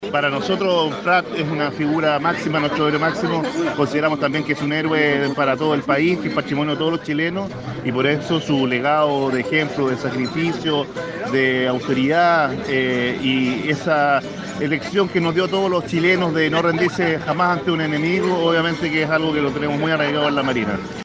Con ceremonia y desfile en Plaza de Armas de Osorno fue conmemorado un nuevo aniversario del Combate Naval de Iquique, en el mes del mar.
El Alcalde de Osorno, Jaime Bertín consideró que la Armada hizo una buena presentación en un día tan importante para todos los chilenos. Por su parte el Comandante en Jefe de la Quinta Zona Naval, Contralmirante, Carlos Fiedler puso de relieve las cualidades que transformaron a Arturo Prat en un héroe, luchando por la Patria.